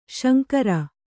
♪ śankara